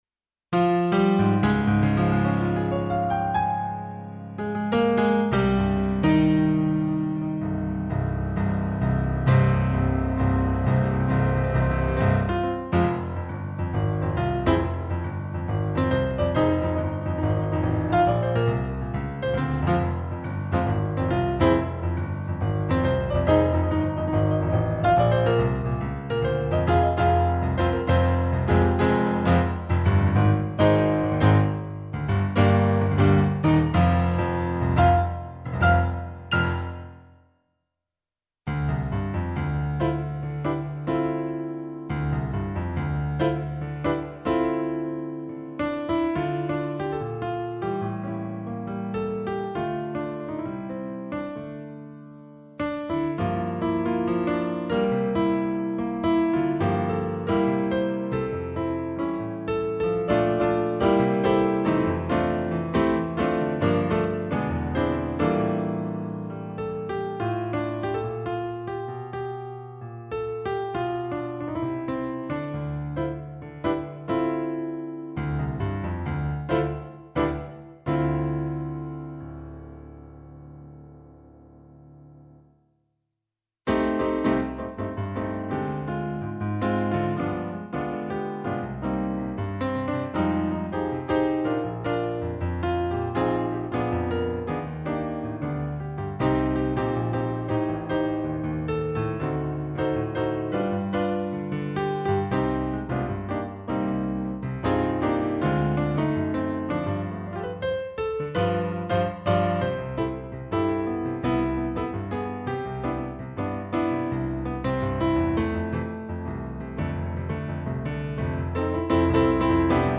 • Middagspianist
• Solomusiker